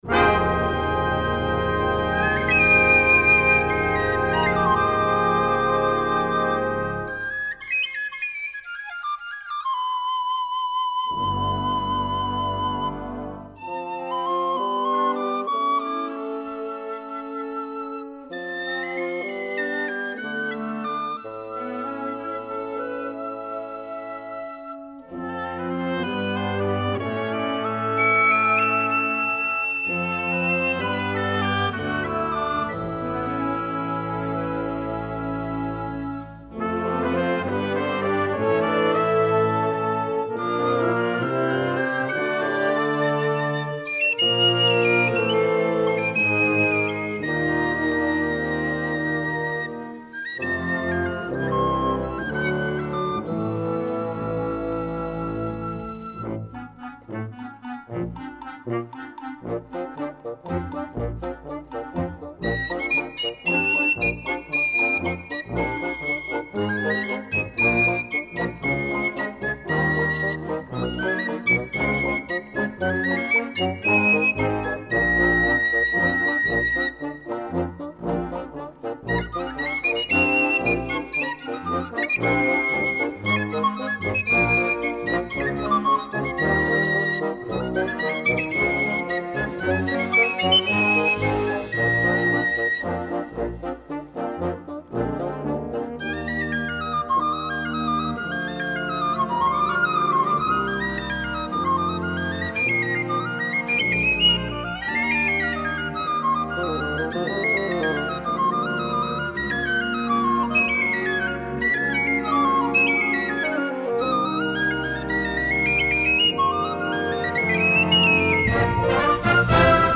Voicing: Piccolo and Concert Band